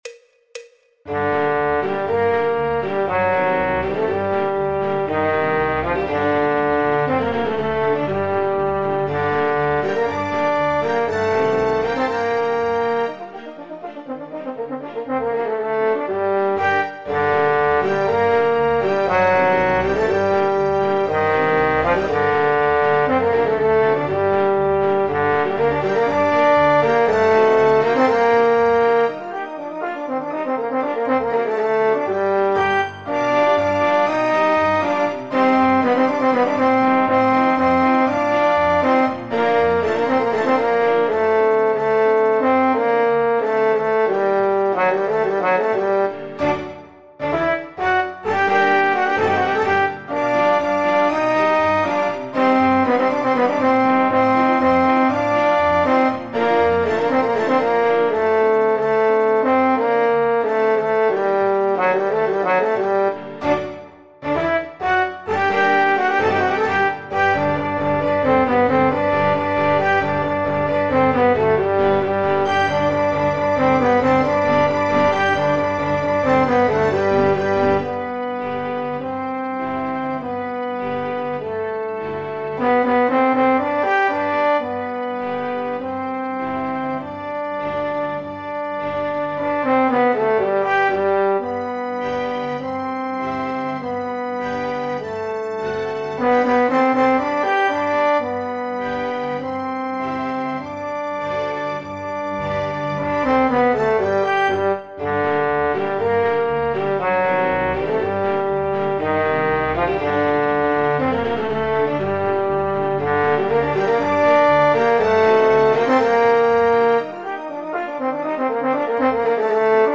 en Sol menor Tonalidad Original
El MIDI tiene la base instrumental de acompañamiento.
Popular/Tradicional
Trombón / Bombardino